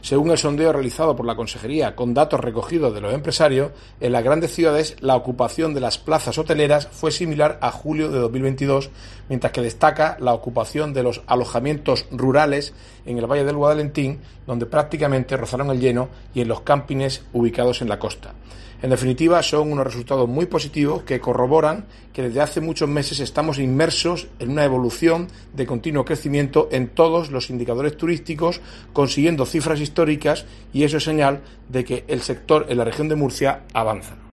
Marcos Ortuño, consejero en funciones de turismo